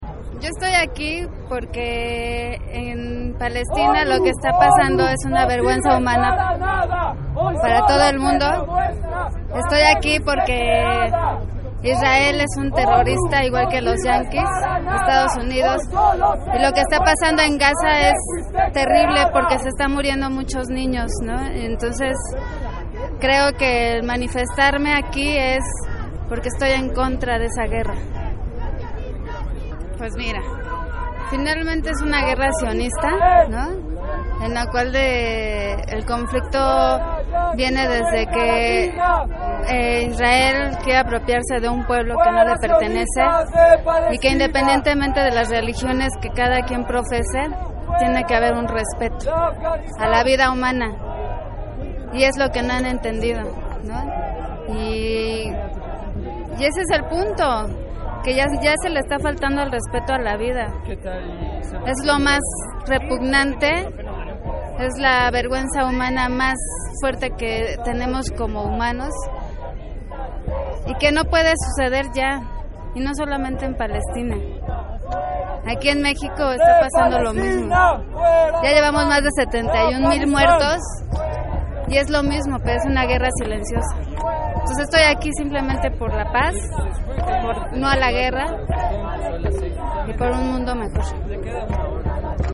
Entrevista manifestante